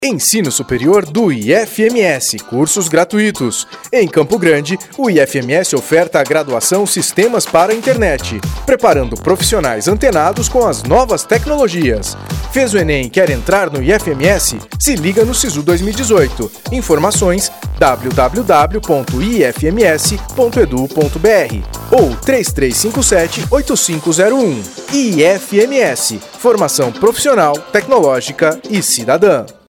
Comerciais enviados às rádios de todo o Estado para divulgação.